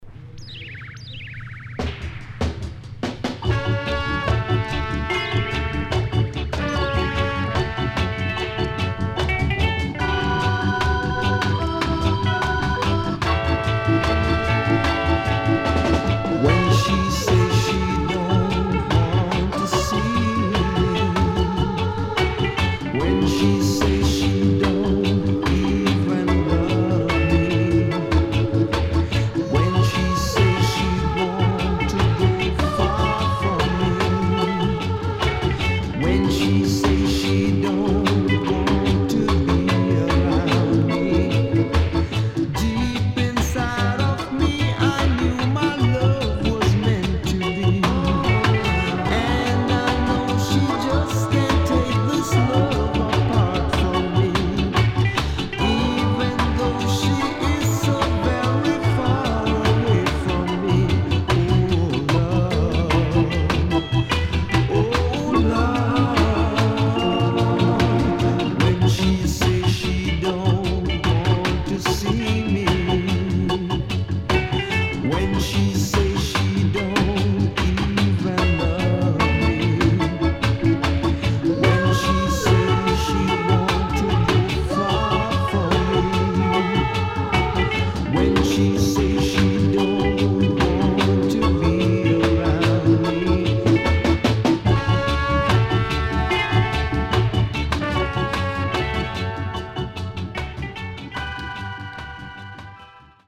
SIDE A:少しチリノイズ入りますが良好です。
SIDE B:少しチリノイズ入りますが良好です。